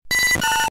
Cri de Papilusion K.O. dans Pokémon Diamant et Perle.